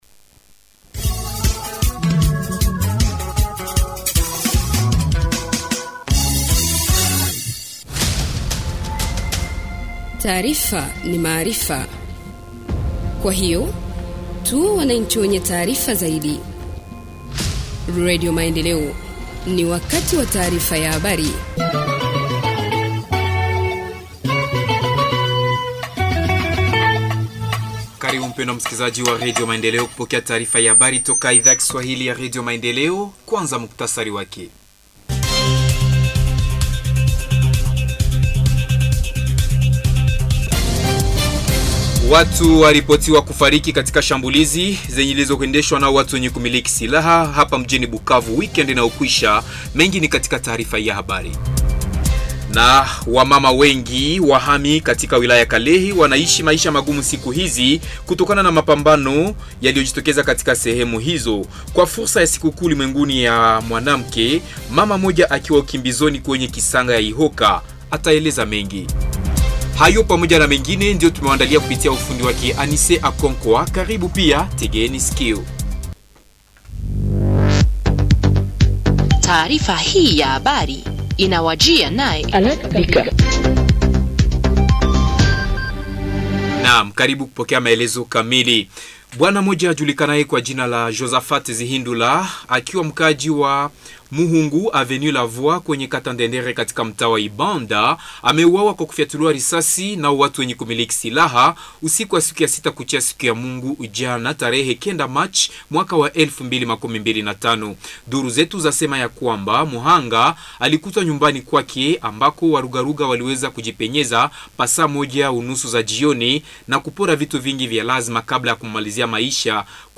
Journal en Kiswahili 10 Mars 2025 – Radio Maendeleo